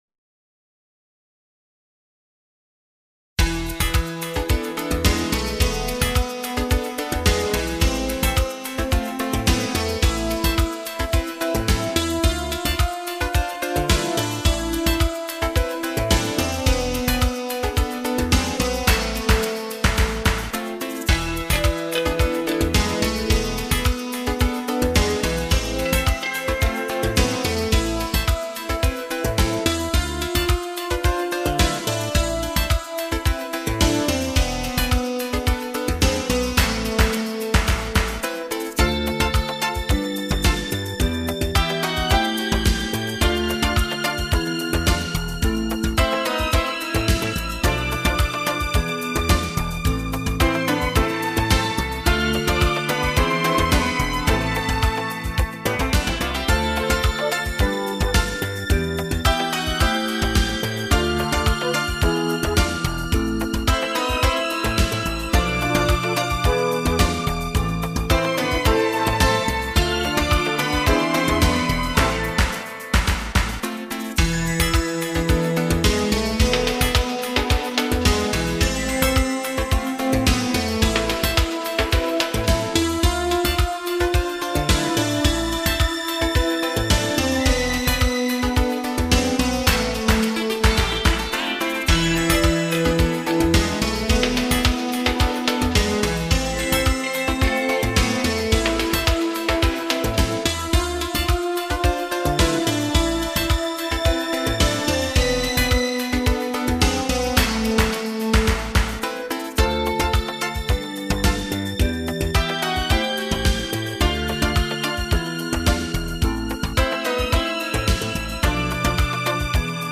موسیقی اینسترومنتال موسیقی بی کلام